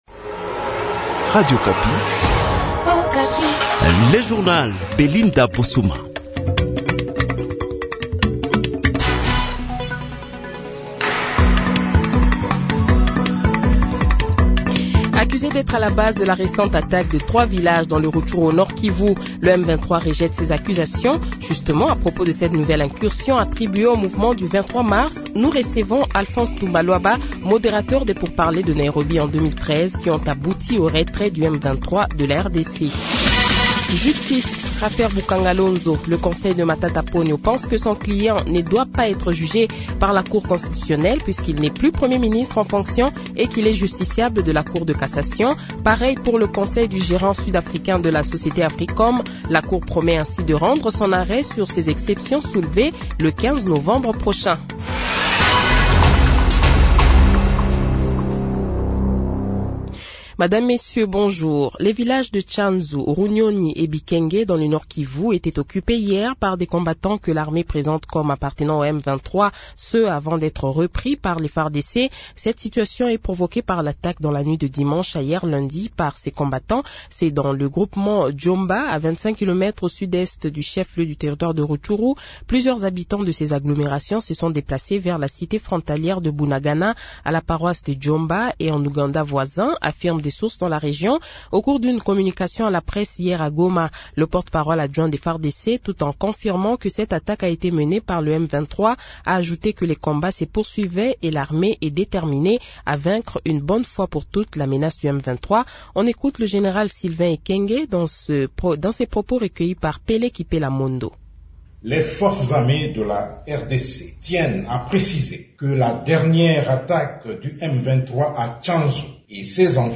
Journal Matin
Le Journal de 7h, 09 Novembre 2021 :